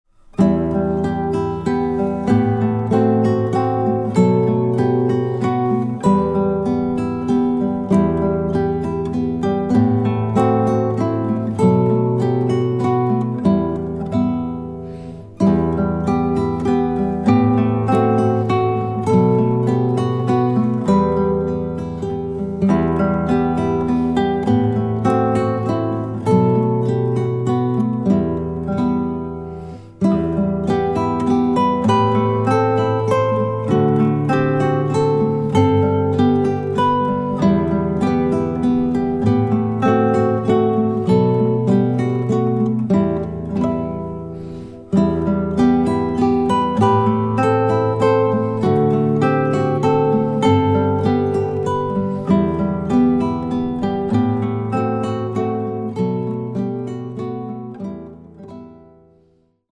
Largo Konzert f Gitarre in D Dur (A Vivaldi)